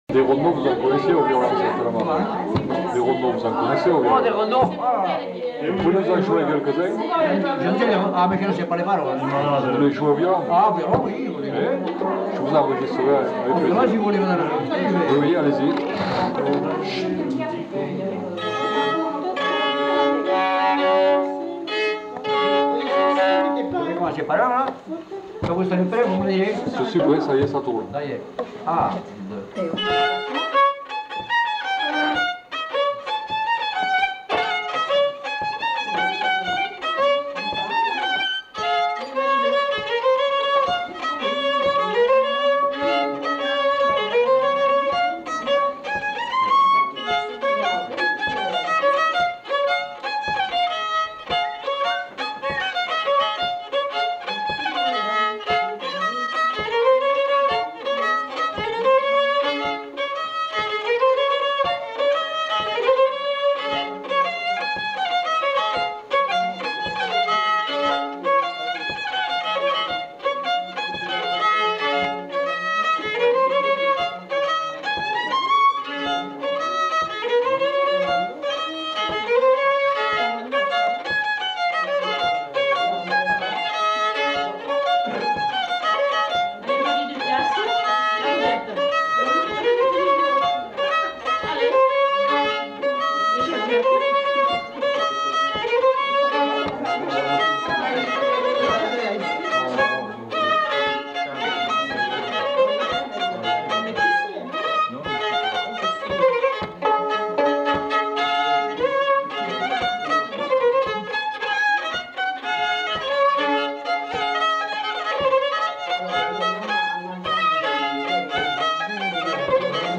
Aire culturelle : Lugues
Lieu : Allons
Genre : morceau instrumental
Instrument de musique : violon
Danse : rondeau